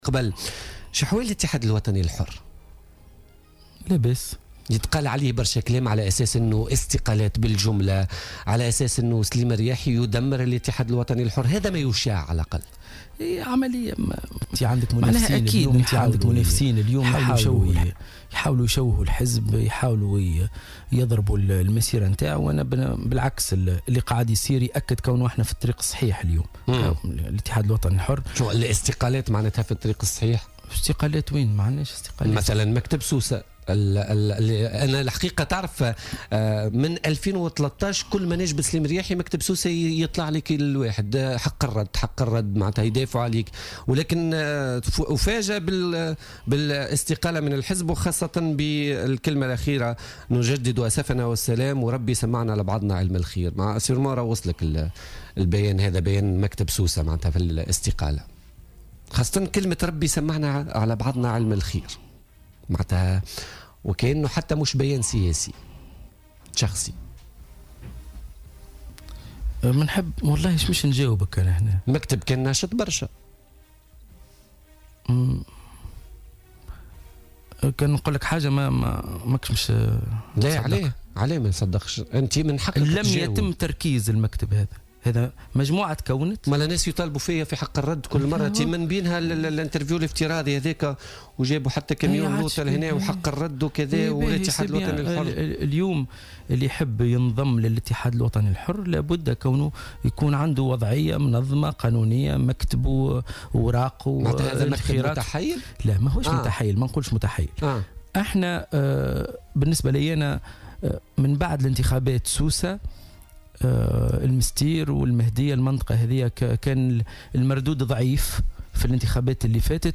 وأكد ضيف "بوليتيكا" أنه لم يتم تركيز أي مكتب يمثل الحزب في سوسة حتى يتم الحديث عن استقالة هذا المكتب.